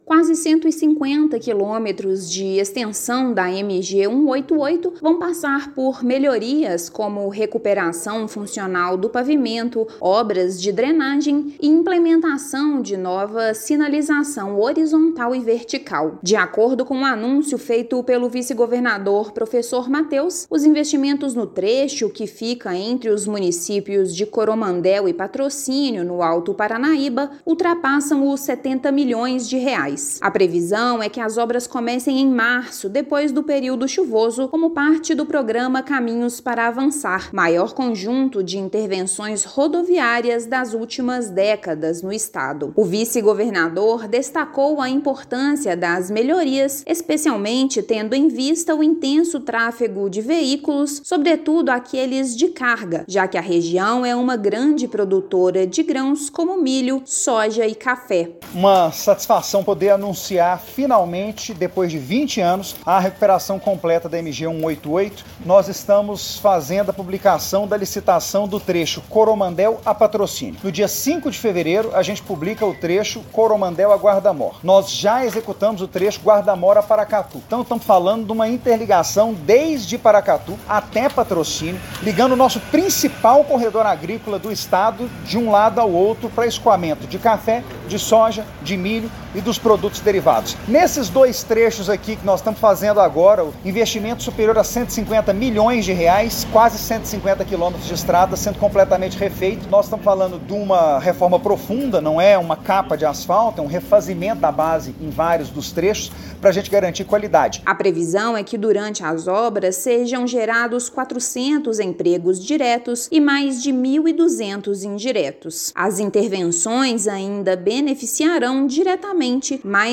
[RÁDIO] Governo de Minas licita obras para recuperar dois trechos da MG-188, na região do Alto Paranaíba